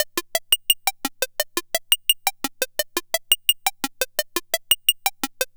70s Random 086-F#.wav